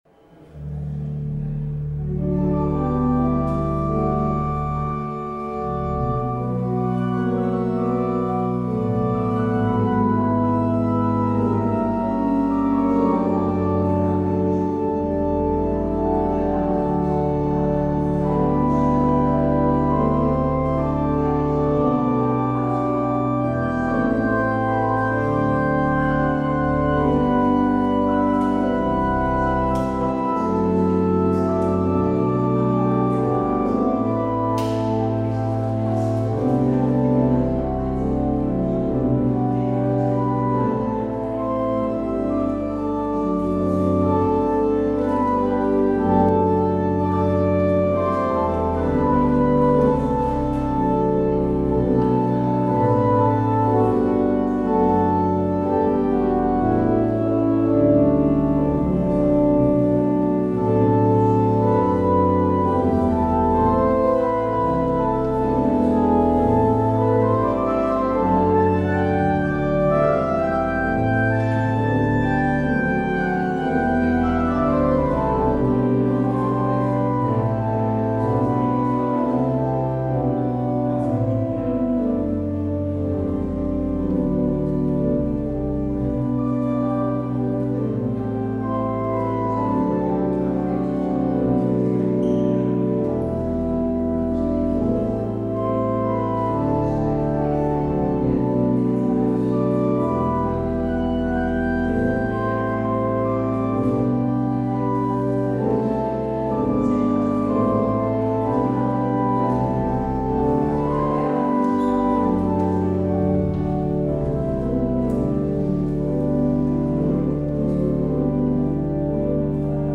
 Luister deze kerkdienst hier terug